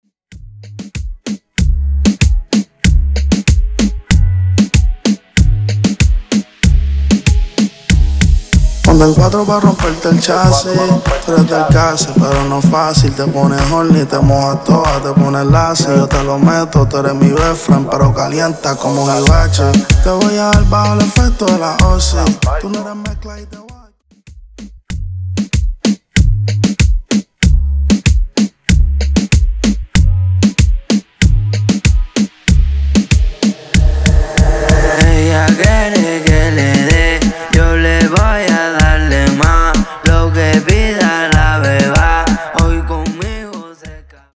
Extended Dirty Intro, Coro